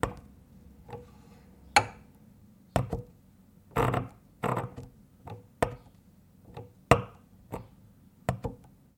描述：1916年Victor Victrola VVXI留声机。 将针臂的各种取出降低到记录上。当针放入其静止位置时，会发生更多的金属咔哒声。包括一些划痕，因为针无意中在记录表面上移动。 麦克风的位置在机器上方指向转盘。 用Sennheiser MKH416录制成Zoom F8录音机。